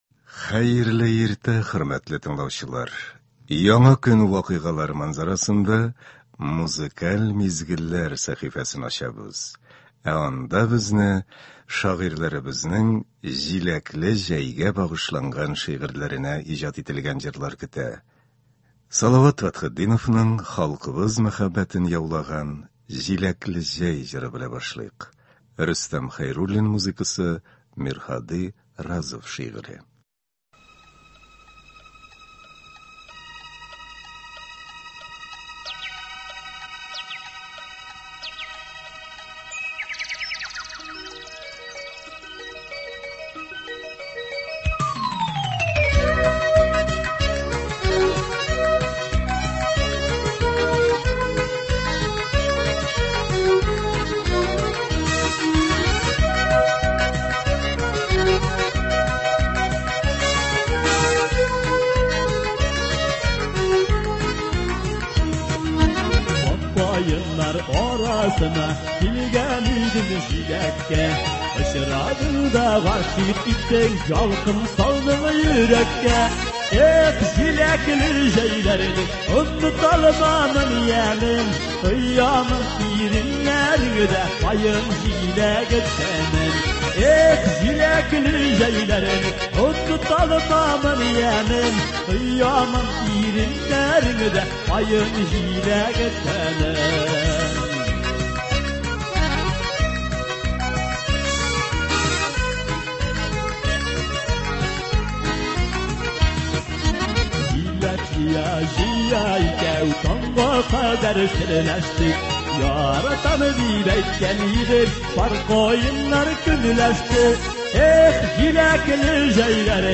Бүгенге иртәнге концертта яраткан җырларыбыз яңгырый.